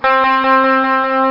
Guitar Reverb Sound Effect
Download a high-quality guitar reverb sound effect.
guitar-reverb-1.mp3